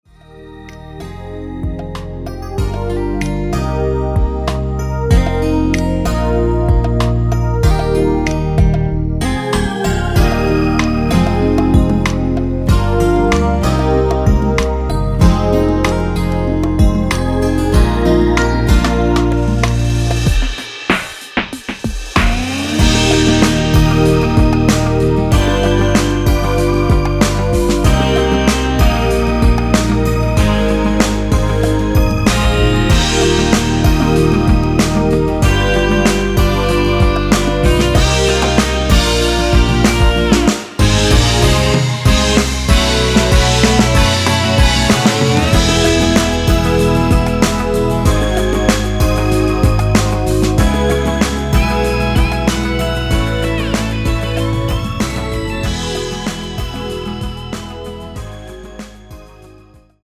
원키에서(-1)내린 (짧은편곡) MR입니다.(미리듣기 참고)
Eb
앞부분30초, 뒷부분30초씩 편집해서 올려 드리고 있습니다.
곡명 옆 (-1)은 반음 내림, (+1)은 반음 올림 입니다.